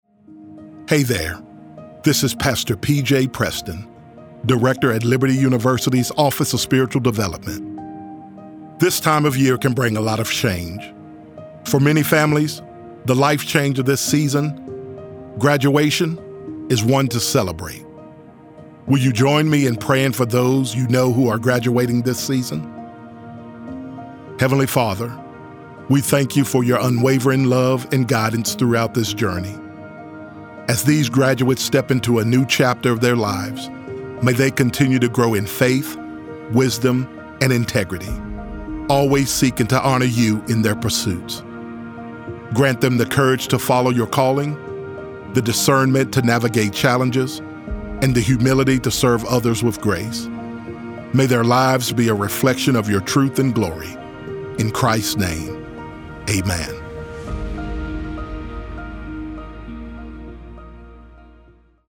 Prayer for College Grads 1